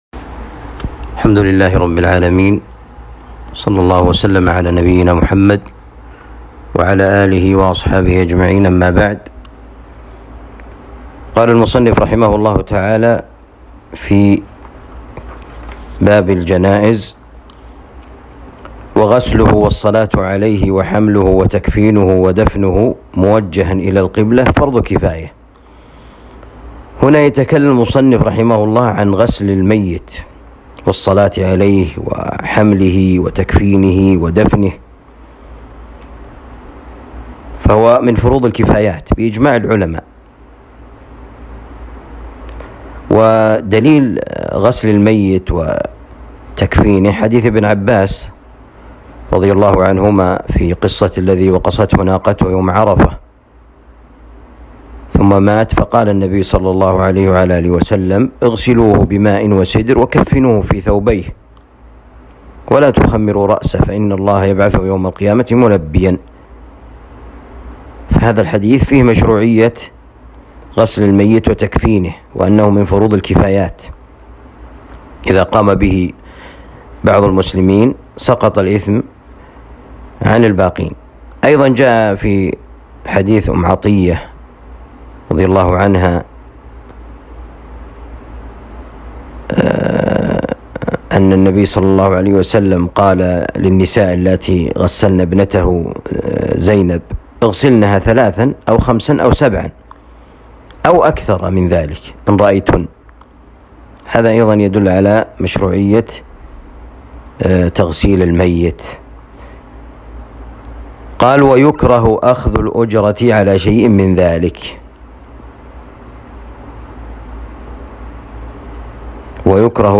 الدروس